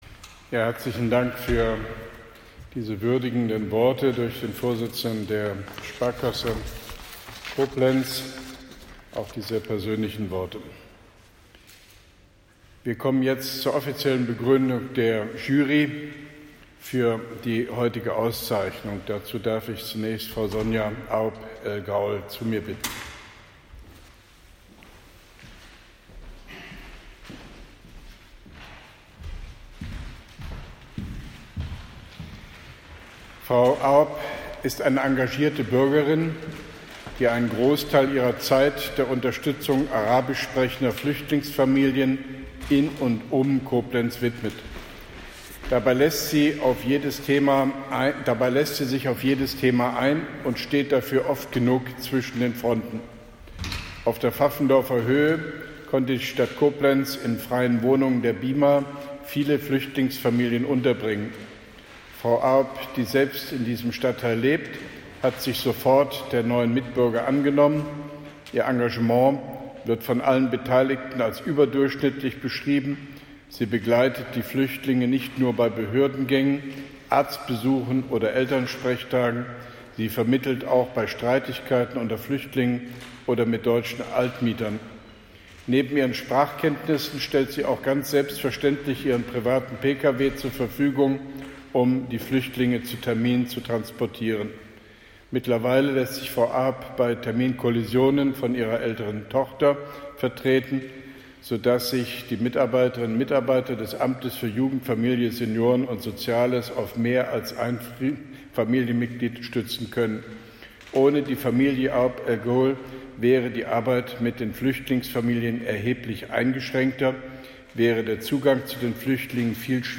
Zur Begründung der Jury (1): OB Hofmann-Göttig bei der Verleihung des “Bürgerpreis 2017”, Koblenz 07.09.2017